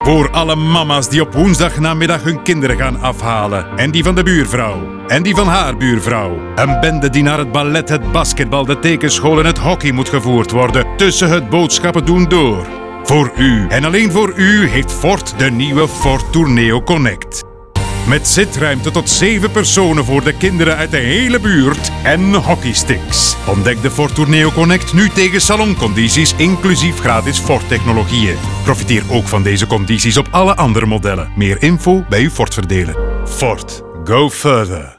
In twee epische radiospots eert Ford op heroïsche wijze alle mama’s en papa’s die elke woensdagnamiddag en in het weekend van hot naar her moeten rijden. De logische boodschap is natuurlijk dat de Ford Tourneo Connect de perfecte partner is om in dat wekelijkse opzet te slagen.
Radio Production: Raygun